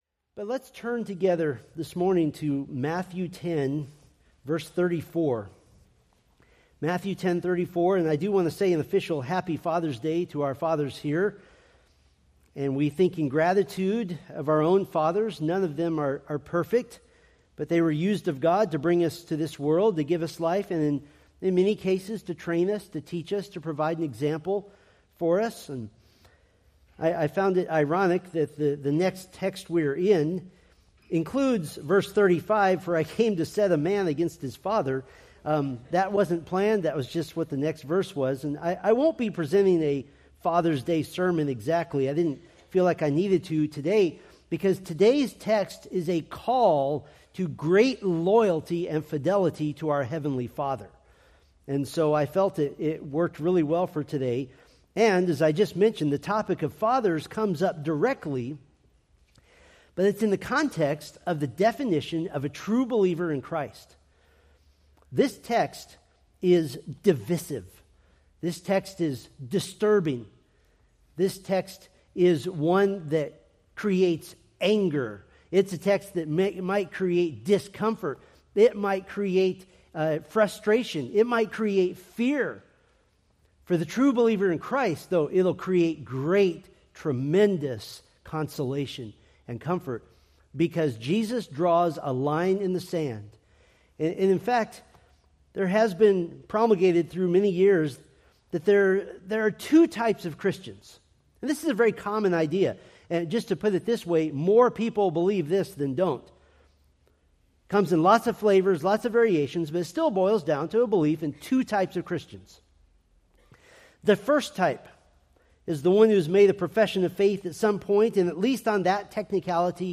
Preached June 15, 2025 from Matthew 10:34-39